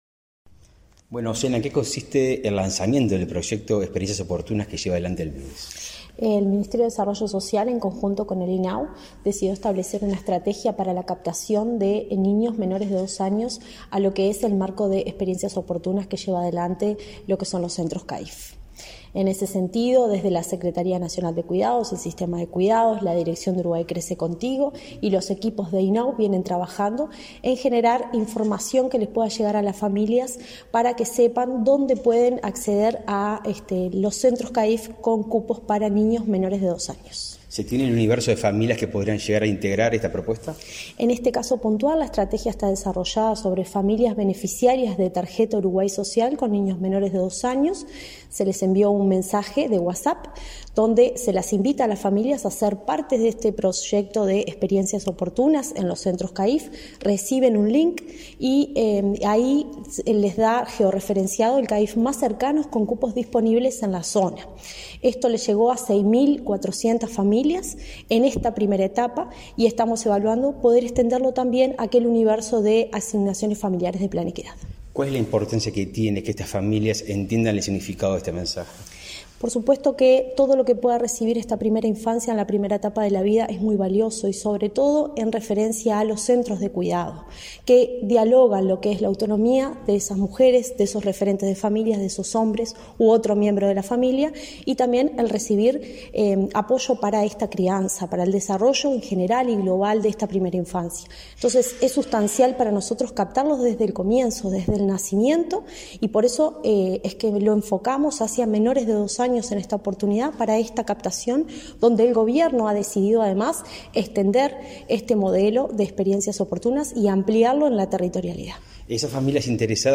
Entrevista a la directora de Desarrollo Social, Cecilia Sena